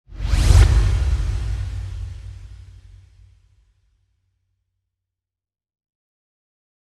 Electronic Swoosh Sound Effect Free Download
Electronic Swoosh